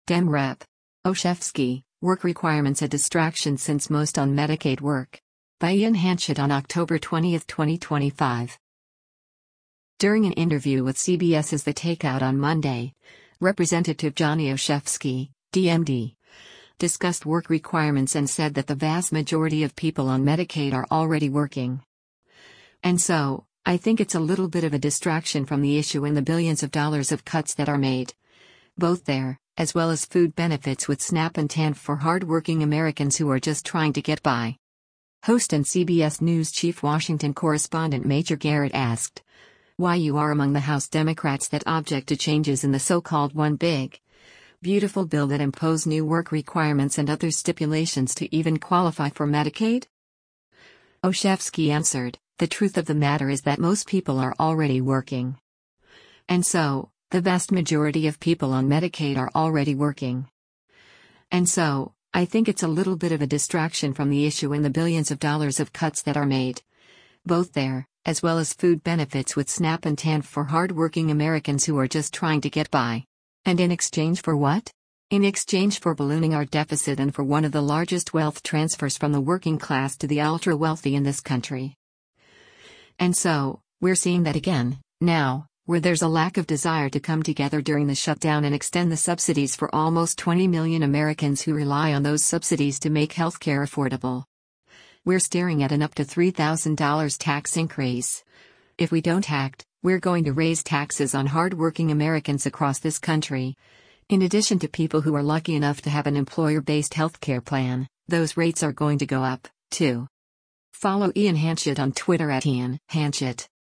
During an interview with CBS’s “The Takeout” on Monday, Rep. Johnny Olszewski (D-MD) discussed work requirements and said that “the vast majority of people on Medicaid are already working.
Host and CBS News Chief Washington Correspondent Major Garrett asked, “[Y]ou are among the House Democrats that object to changes in the so-called one big, beautiful bill that impose new work requirements and other stipulations to even qualify for Medicaid?”